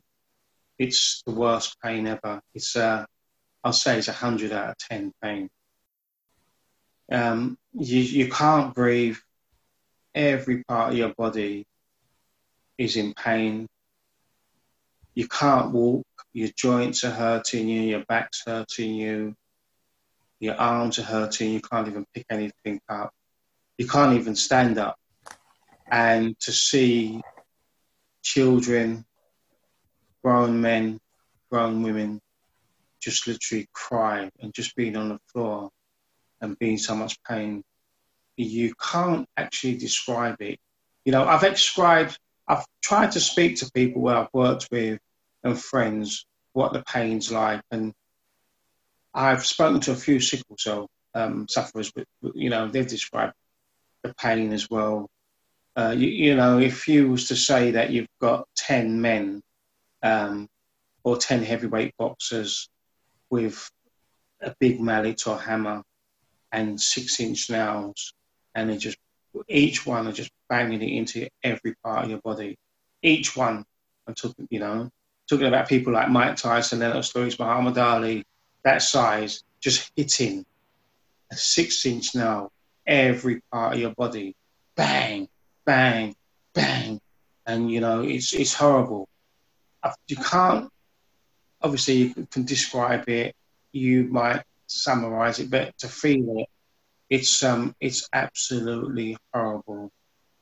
In this section, explore archives and photographs depicting life with the condition, and listen to oral histories of people talking about what sickle cell crises feel like, how they manage work, how they want to be seen, and how they keep going.